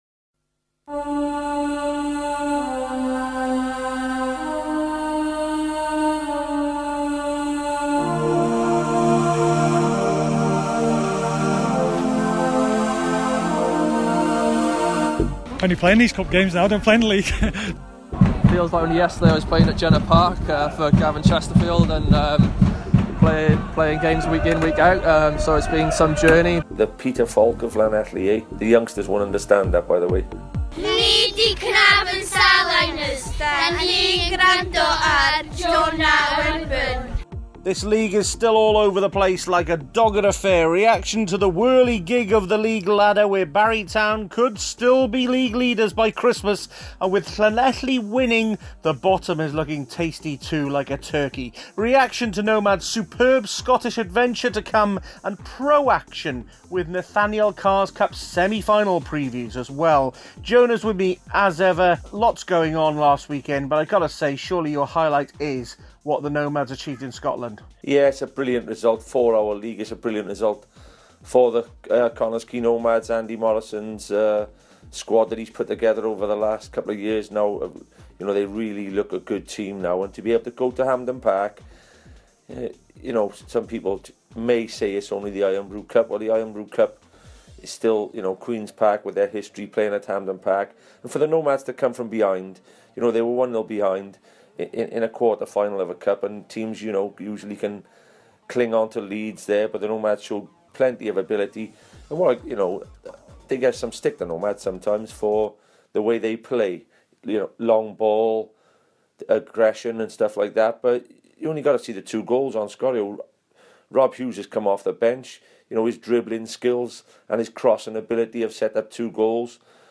WPL legend Christian Doidge speaks to us on a rare weekend off as he watches Barry V TNS at Jenner Park.